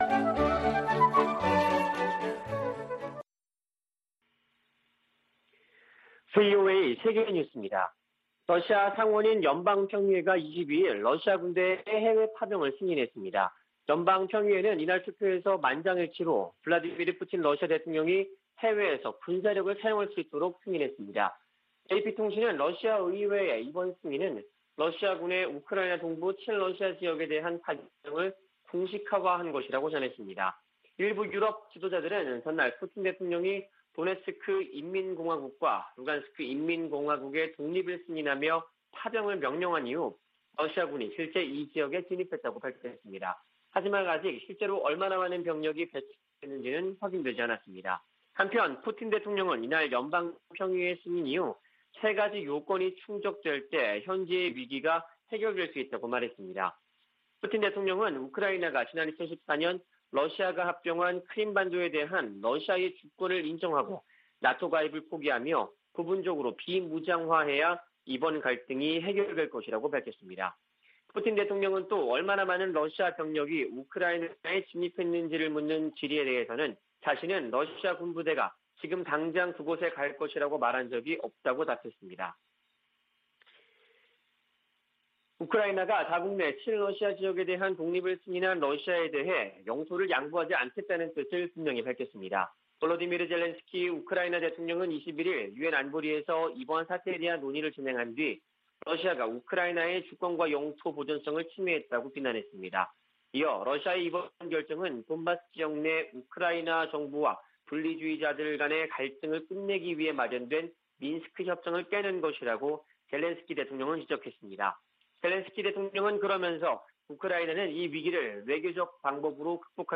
VOA 한국어 아침 뉴스 프로그램 '워싱턴 뉴스 광장' 2021년 2월 23일 방송입니다. 토니 블링컨 미 국무장관이 왕이 중국 외교부장과 북한 문제와 우크라이나 사태 등에 관해 전화협의했습니다. 조 바이든 미국 행정부가 러시아 군의 우크라이나 침공에 대응하는 조치를 구체화하는 가운데 한국 등 아시아 동맹국의 인도주의 지원 등이 거론되고 있습니다. 김정은 북한 국무위원장이 시진핑 중국 국가주석에게 친서를 보내 대미 공동전선 협력을 강조했습니다.